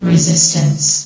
CitadelStationBot df15bbe0f0 [MIRROR] New & Fixed AI VOX Sound Files ( #6003 ) ...
resistance.ogg